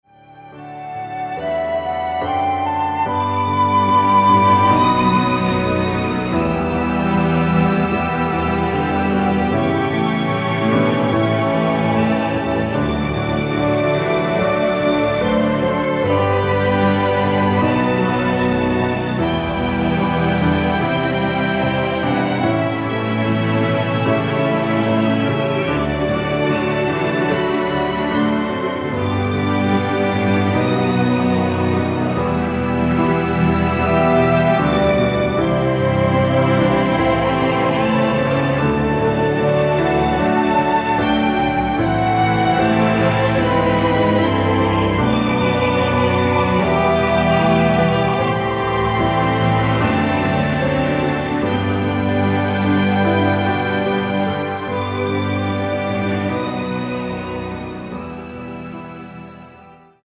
원키에서(-2)내린 코러스 포함된 MR입니다.(미리듣기 확인)
앞부분30초, 뒷부분30초씩 편집해서 올려 드리고 있습니다.
중간에 음이 끈어지고 다시 나오는 이유는